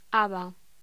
Locución: Haba